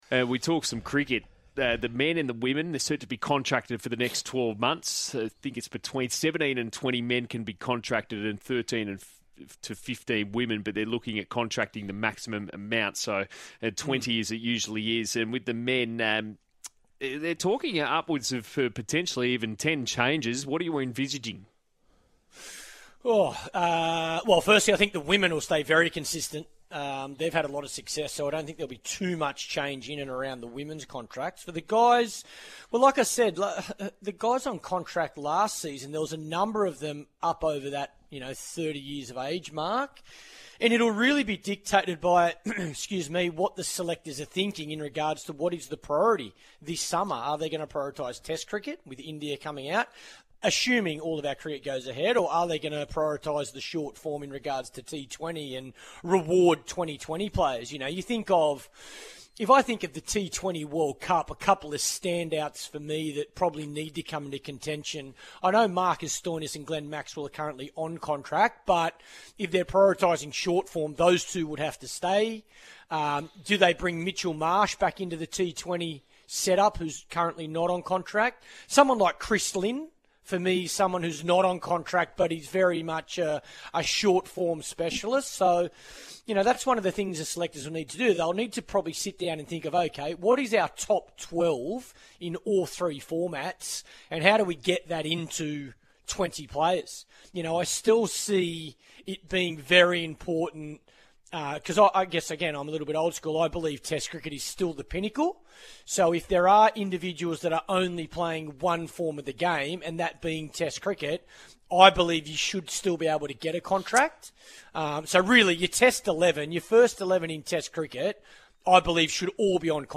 A caller to